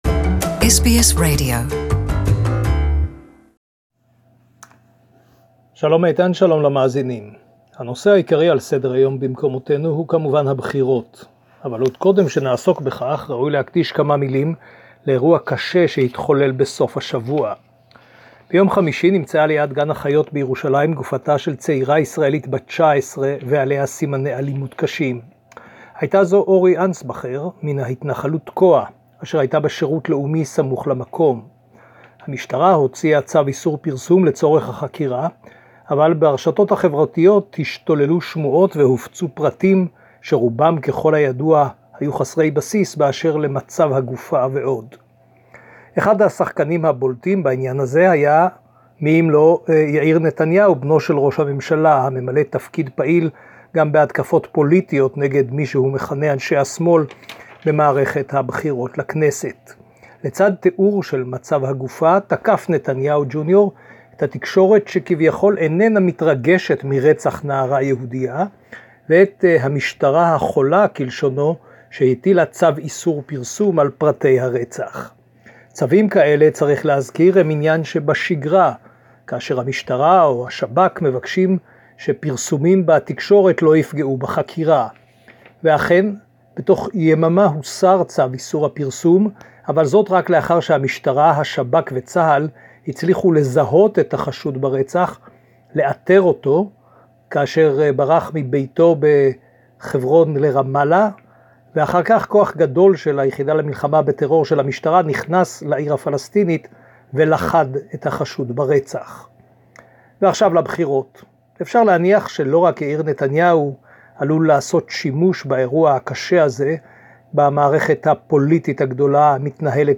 Weekly current news from Jerusalem